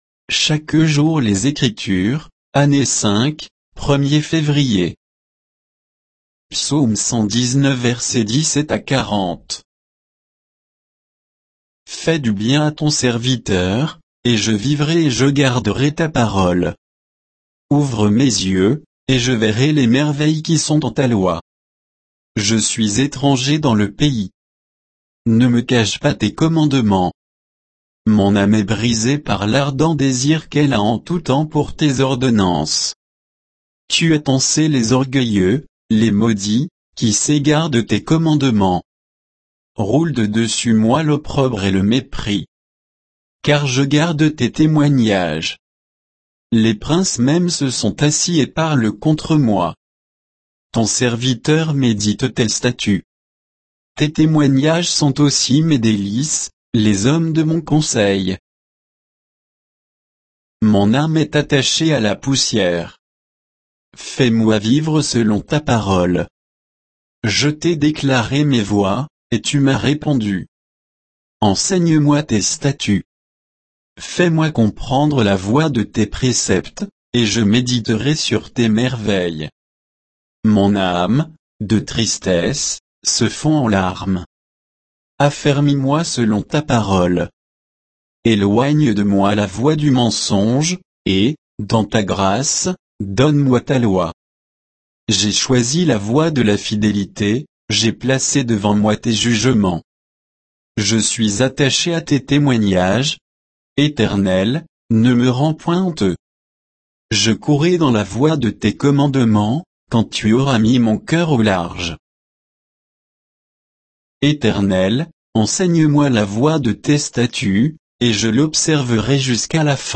Méditation quoditienne de Chaque jour les Écritures sur Psaume 119